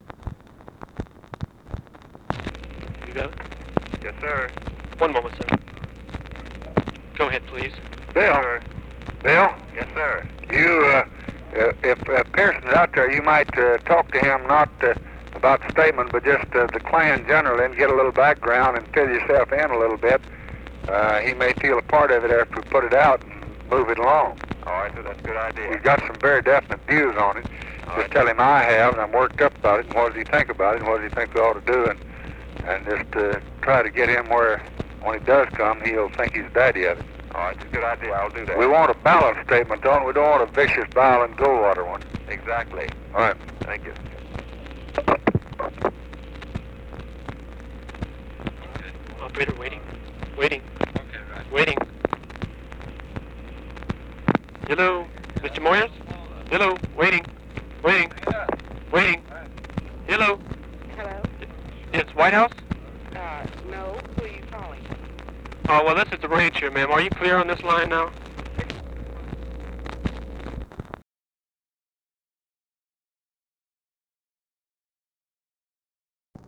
Conversation with BILL MOYERS, July 17, 1964
Secret White House Tapes